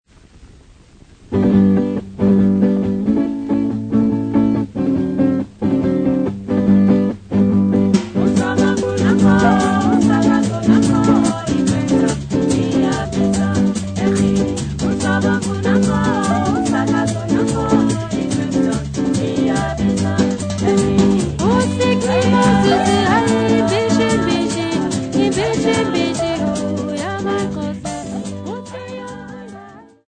Popular music--Africa
sound recording-musical
Indigenous song, accompanied by guitar and rattle